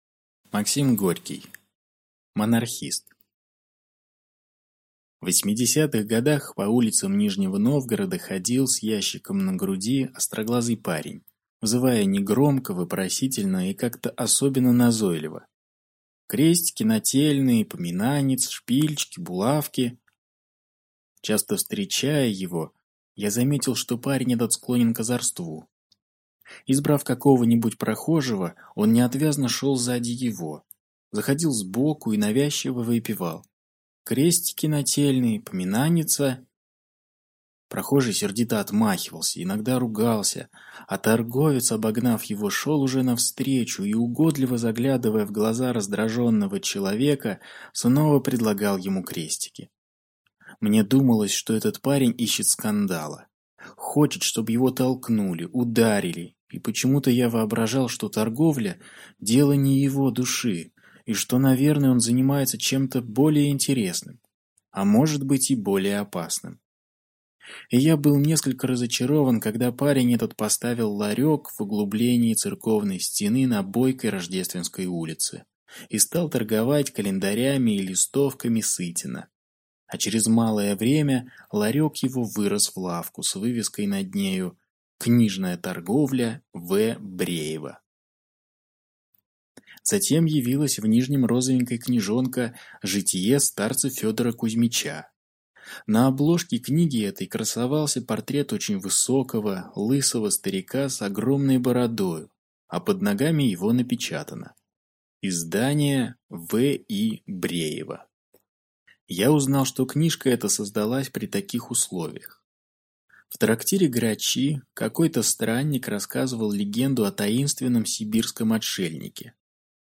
Аудиокнига Монархист | Библиотека аудиокниг
Прослушать и бесплатно скачать фрагмент аудиокниги